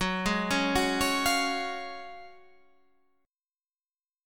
F#M7sus2 Chord